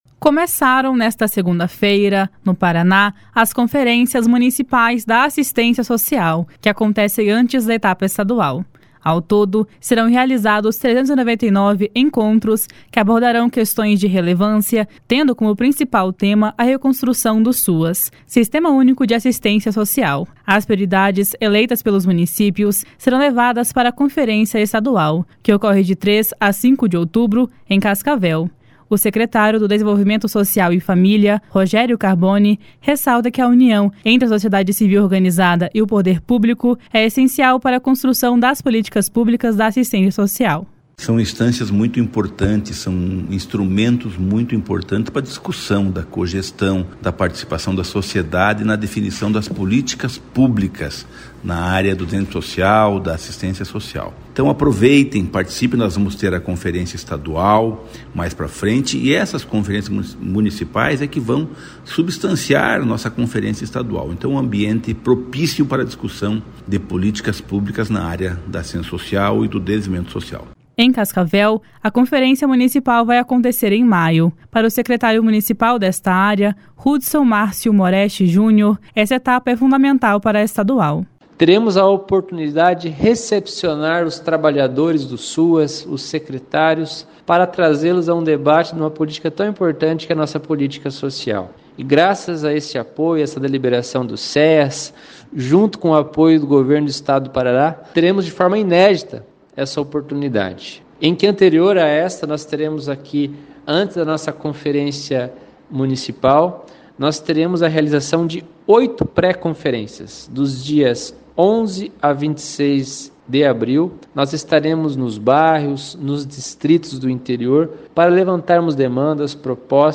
O secretário do Desenvolvimento Social e Família, Rogério Carboni, ressalta que a união entre a sociedade civil organizada e o poder público, é essencial para a construção das políticas públicas da assistência social.
Para o secretário municipal desta área, Hudson Márcio Moreschi Júnior, essa etapa é fundamental para a estadual.